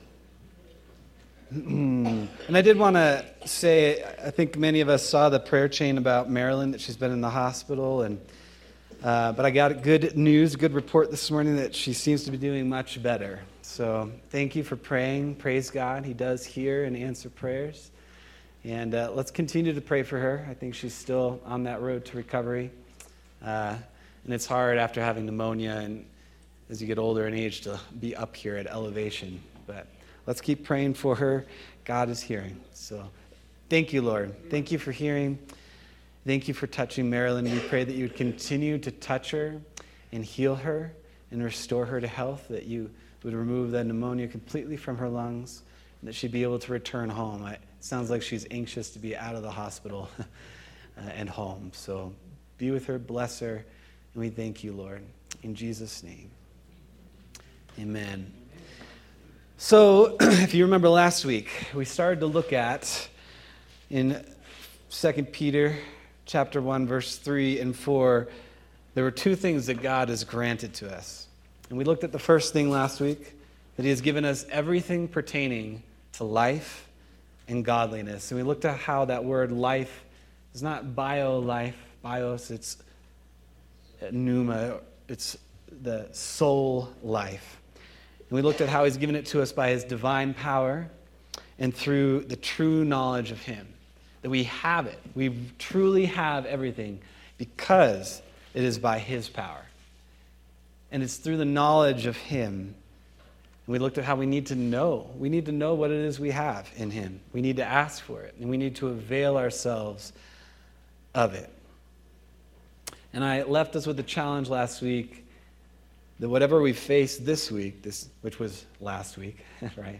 September 14th, 2025 Sermon